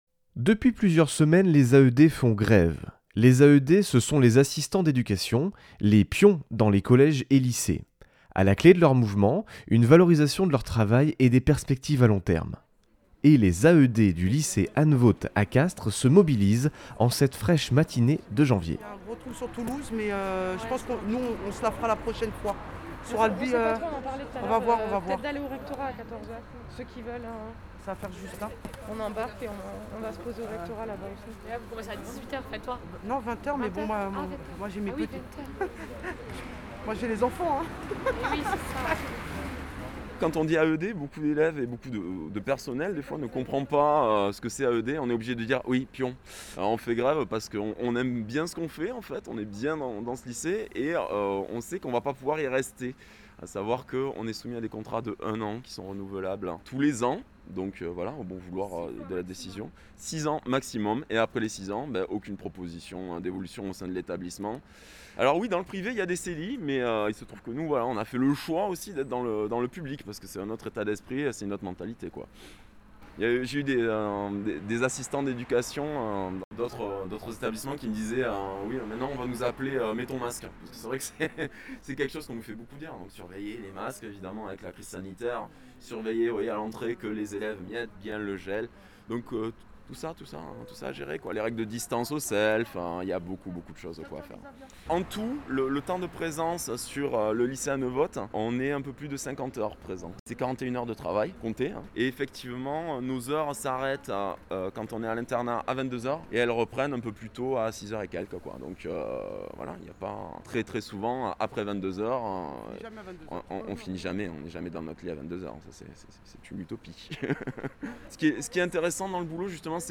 Tous les podcasts > Reportages :